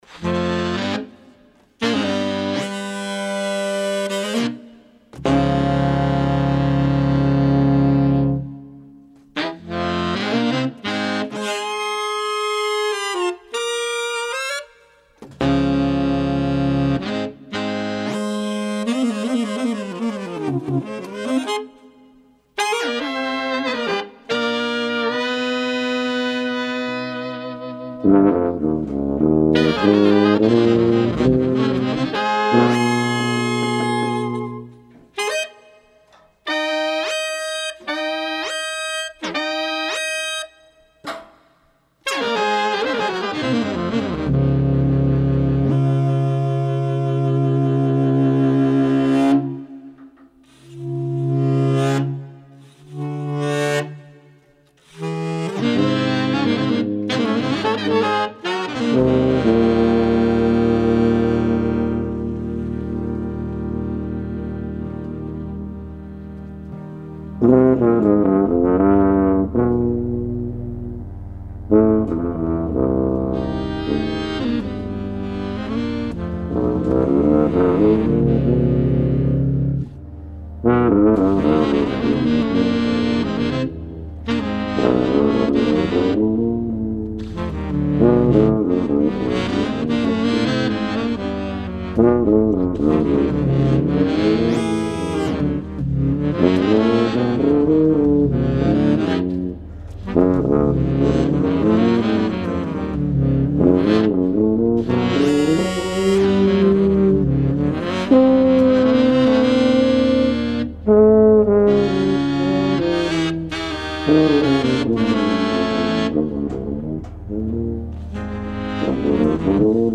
Recorded live at Downtown Music Gallery in Manhattan
tuba
alto saxophone, electronics
Stereo (722 / Pro Tools)